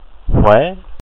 wymowa) − miasto w środkowym Wietnamie, nad rzeką Hương, 11 km od jej ujścia do Morza Południowochińskiego.